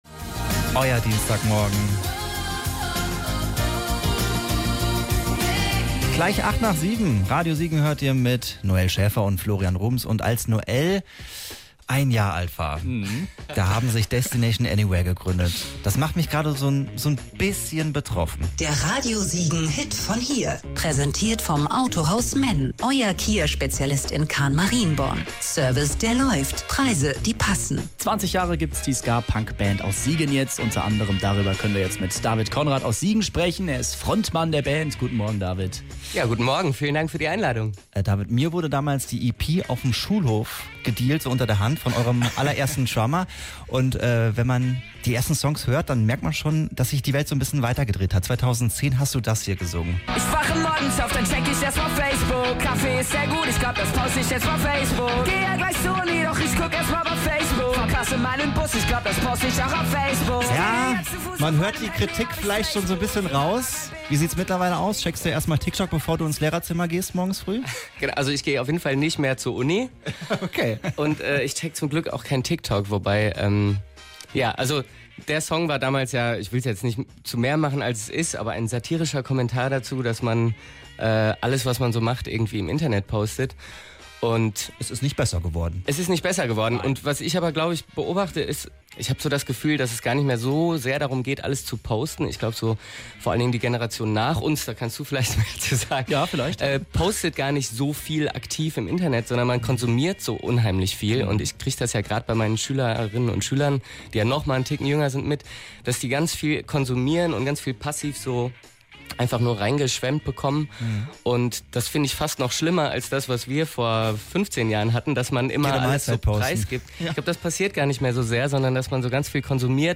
flor---mitschnitt-destination-anywhere.mp3